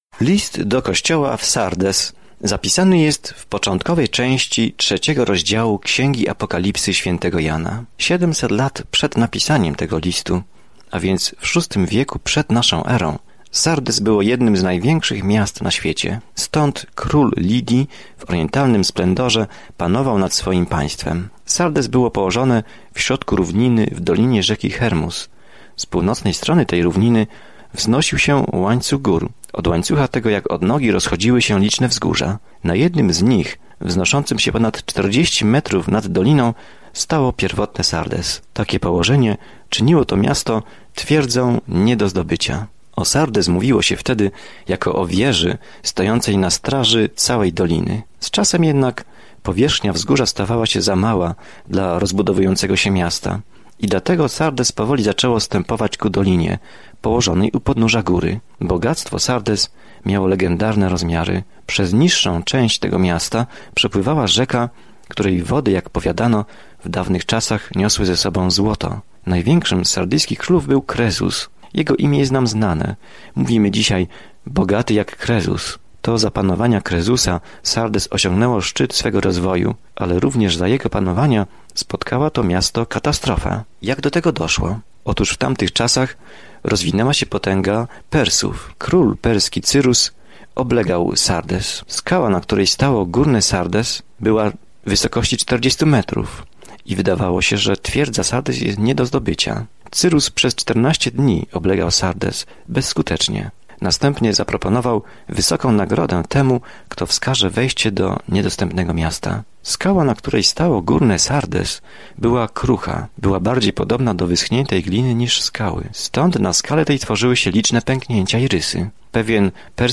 Scripture Revelation 3:1-6 Day 9 Start this Plan Day 11 About this Plan Apokalipsa św. Jana opisuje koniec rozległego planu dziejów, przedstawiając obraz tego, jak zło zostanie ostatecznie uporane, a Pan Jezus Chrystus będzie rządził z całą władzą, mocą, pięknem i chwałą. Codziennie podróżuj przez Objawienie, słuchając studium audio i czytając wybrane wersety słowa Bożego.